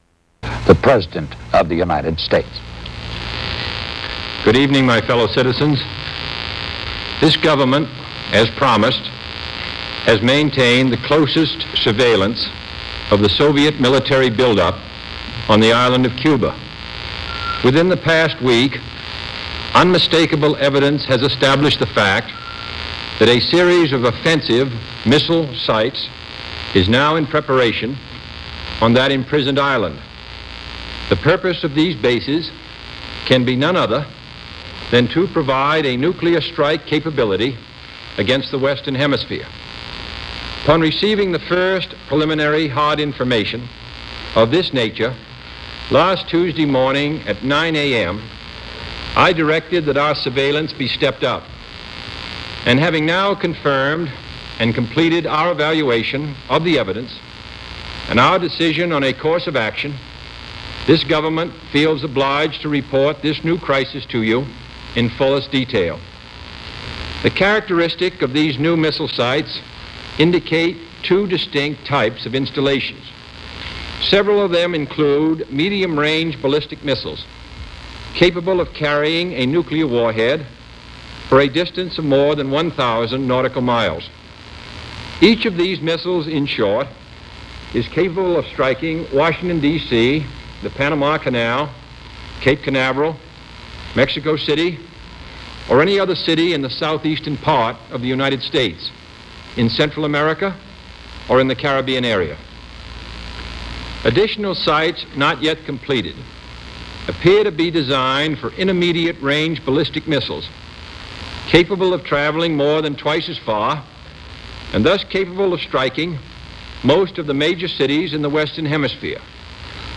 Cuba blockade, an address to the nation